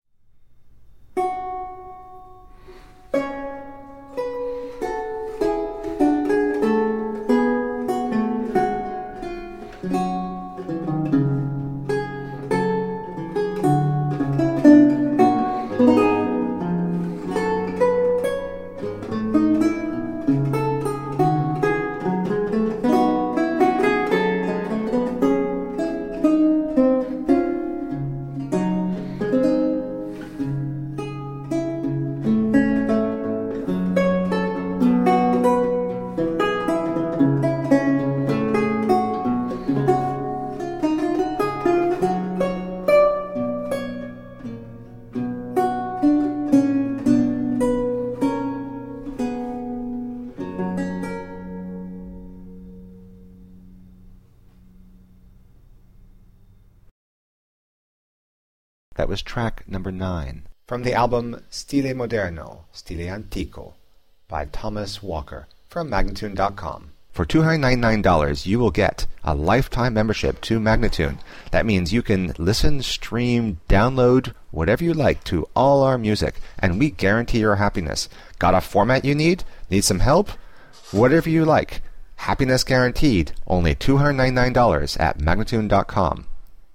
Lute music of 17th century france and italy
Classical, Baroque, Renaissance, Instrumental
Lute